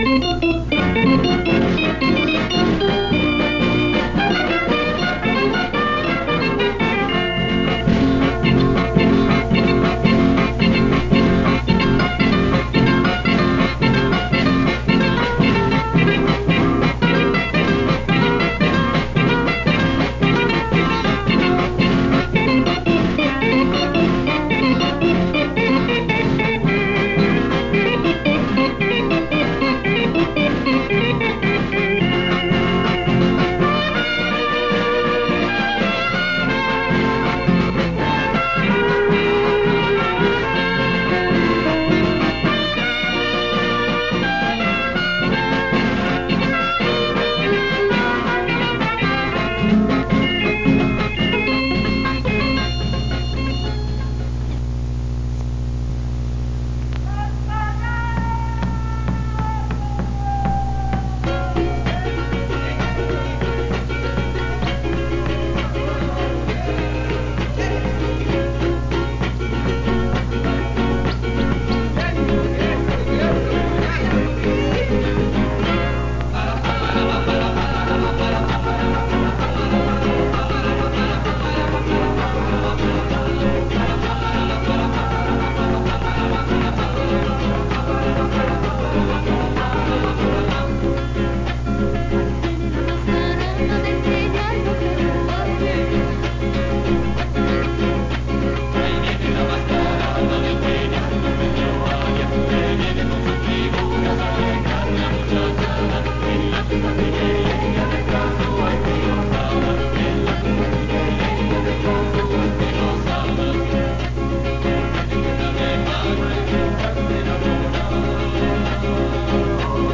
Grabación de música folclórica utilizada por el grupo de bailes folclóricos del Archivo Nacional - Archivo Nacional de Costa Rica
Notas: Casete de audio y digital